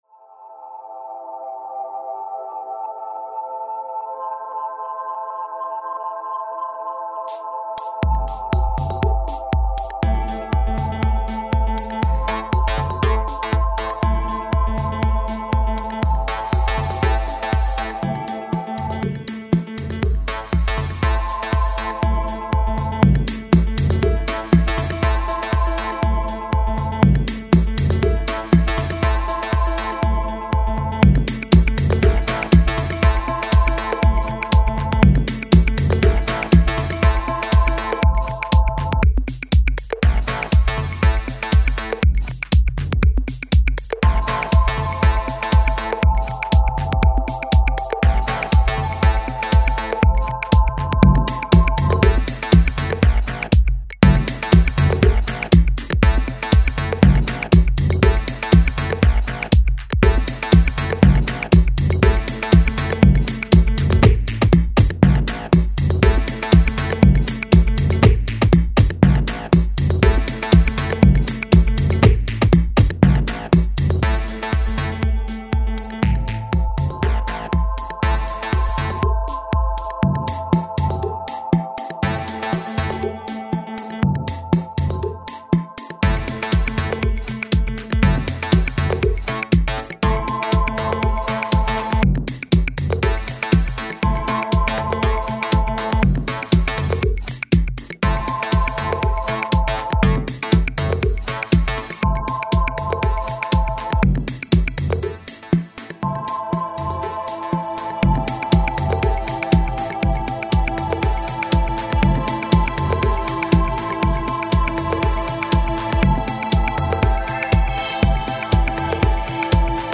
It's called Stopping Time and it has a nice rolling sound.